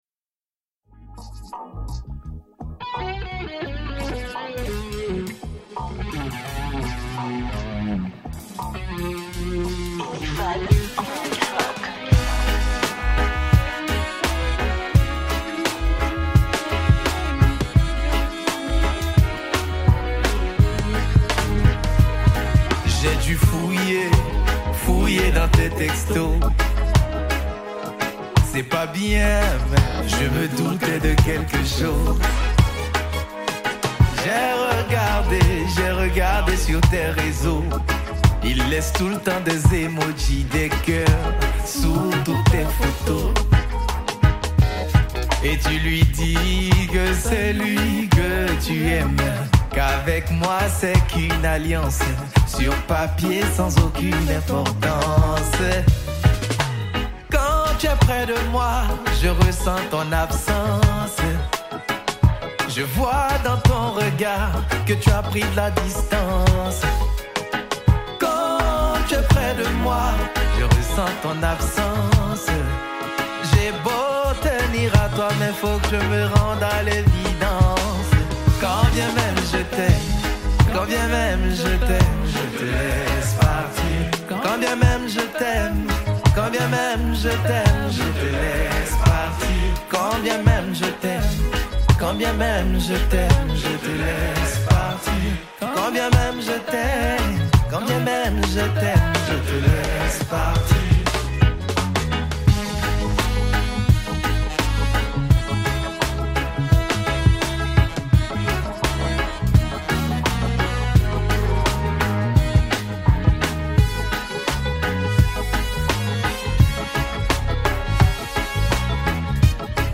| Reggae